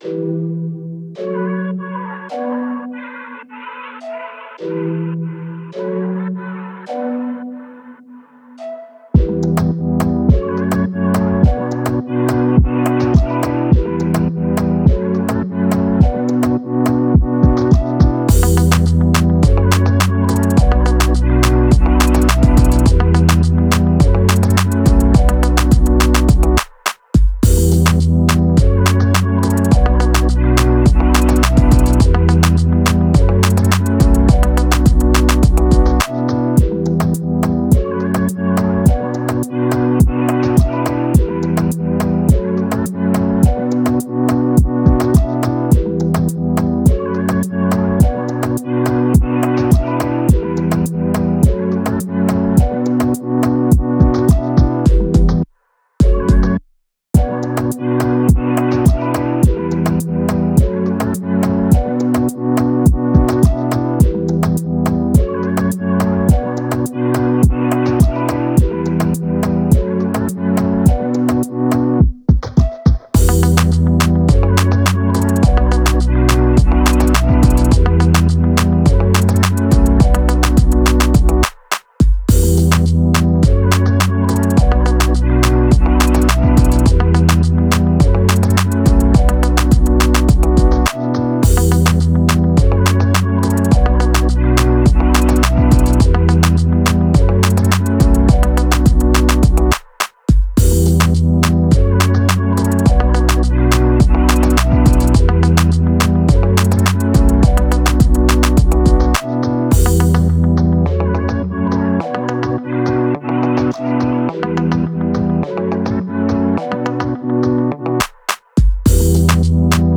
BPM105
NOTAF#m
MOODMelodic
GÉNEROR&B